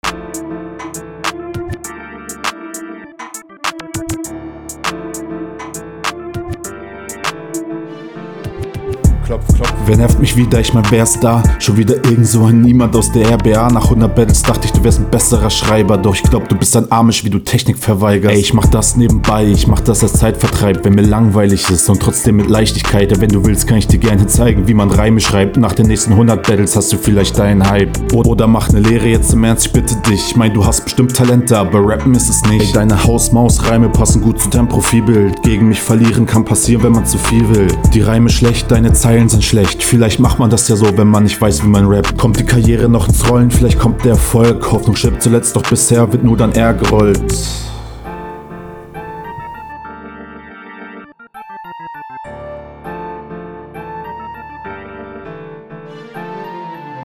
der Beat steht dir safe besser. Fand den flow hier gut.
eh einstieg wirkte direkt bisschen zu spät und nicht on point. flow reime mische technik, …